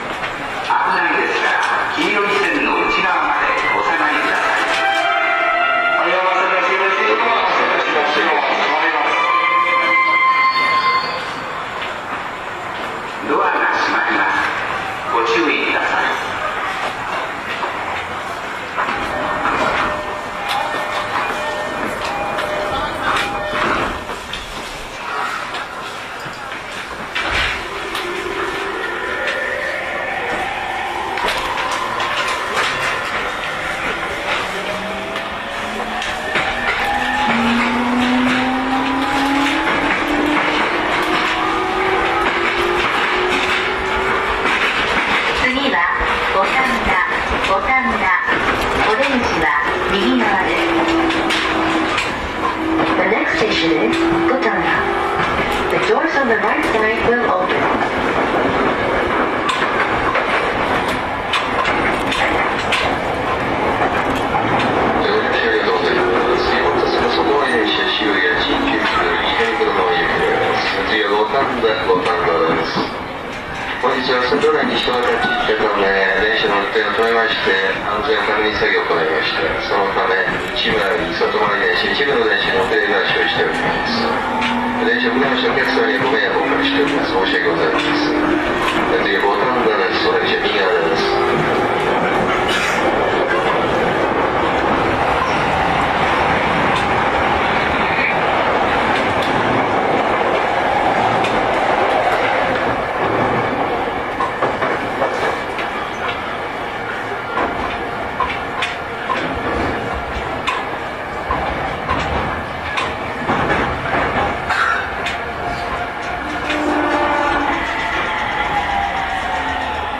走行音
YM10 E231系 大崎-五反田 2:11 9/10 大崎始発です。